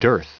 Prononciation du mot dearth en anglais (fichier audio)
Prononciation du mot : dearth